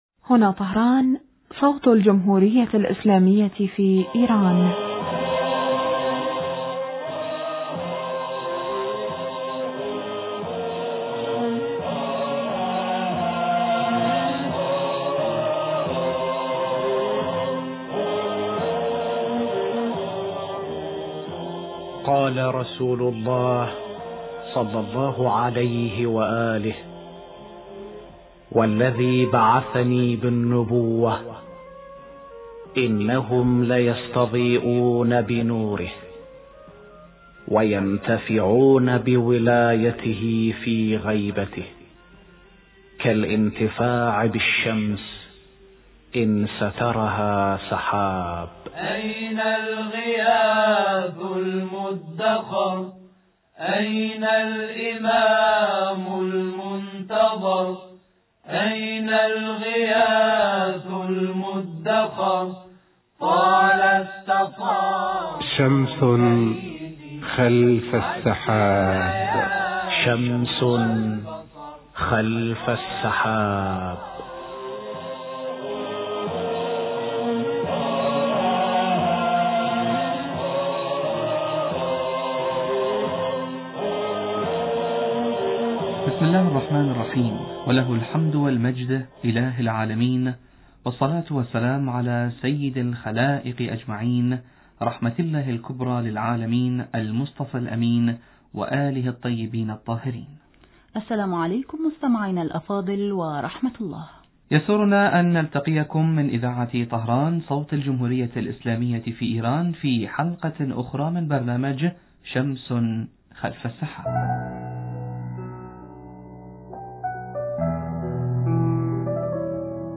يليها اتصال هاتفي بخبير البرنامج وإجابة عن سؤال بشأن منتظري الظهور المهدي من غير الإنس